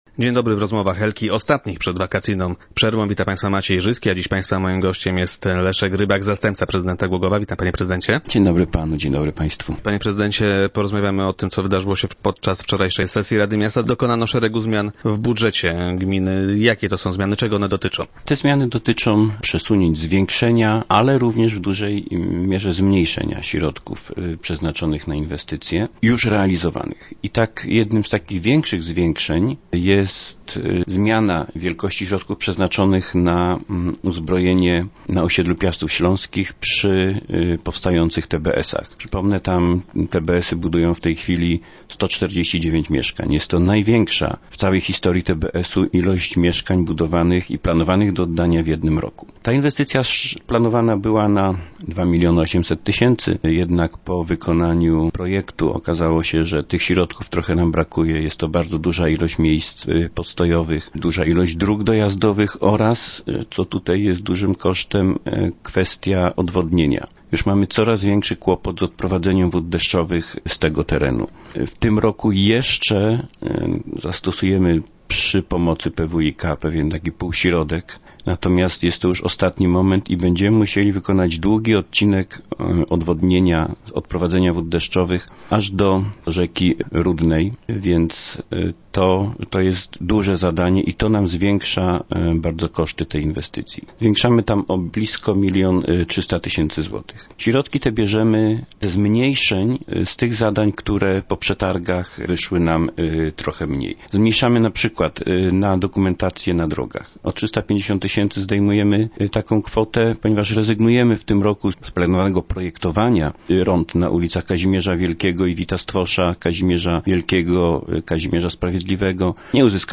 Zdaniem Leszka Rybaka, zastępcy prezydenta i gościa dzisiejszych Rozmów Elki, to konieczność.